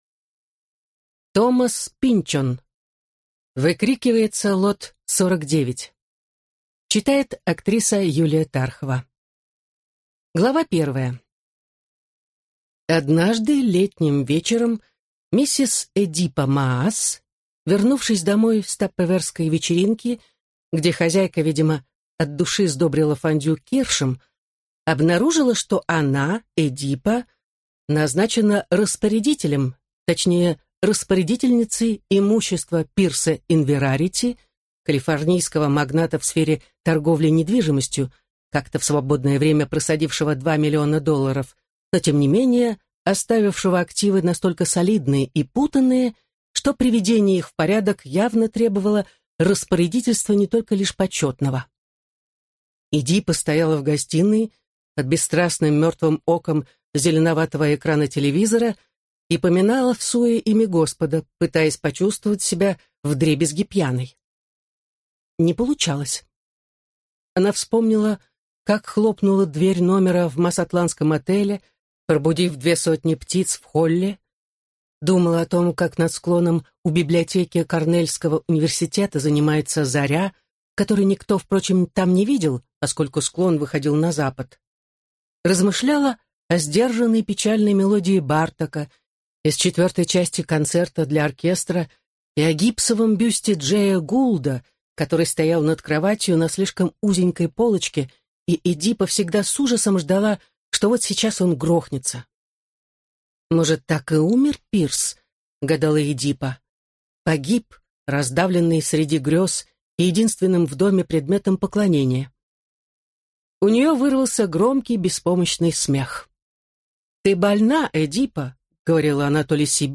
Выкрикивается лот 49 (слушать аудиокнигу бесплатно) - автор Томас Пинчон